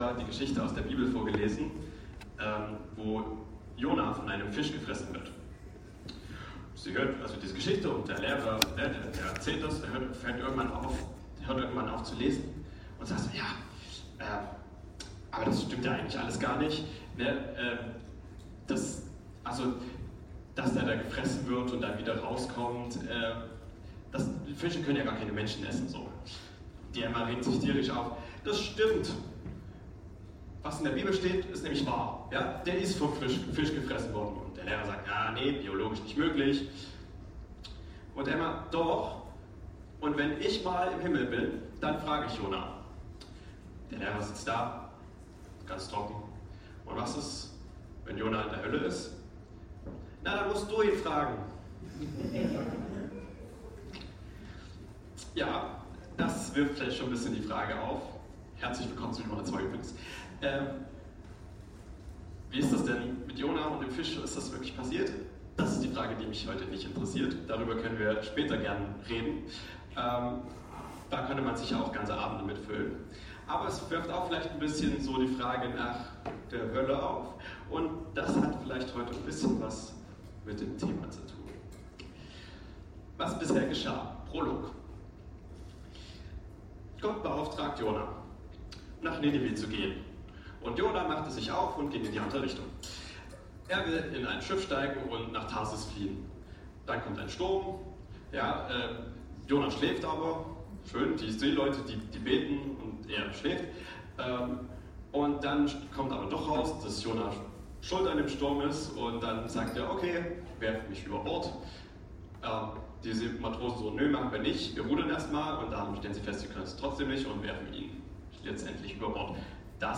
Teil 2 der Predigtreihe über Jona